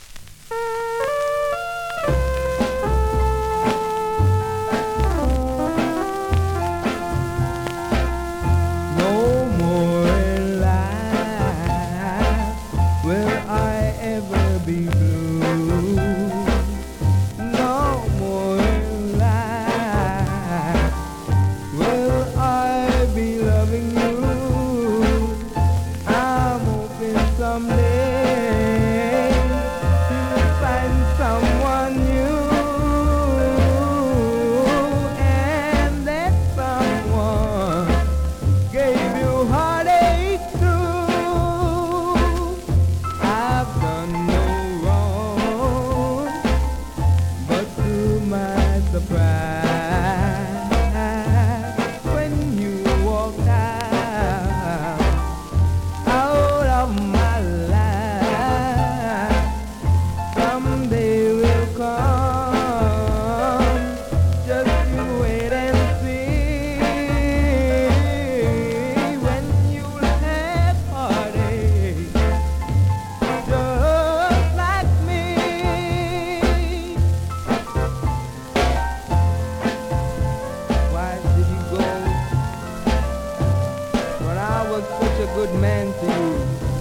コメントレアSKA!!
スリキズ、ノイズ比較的少なめで